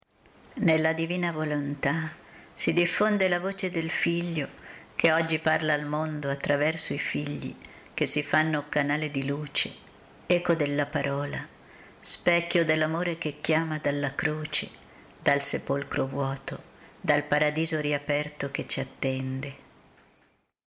24) Preghiera mp3 – Canto nr. 217 Trionferà l’Amore mp3